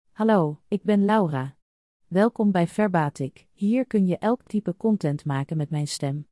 LauraFemale Dutch AI voice
Laura is a female AI voice for Dutch.
Voice sample
Listen to Laura's female Dutch voice.
Laura delivers clear pronunciation with authentic Dutch intonation, making your content sound professionally produced.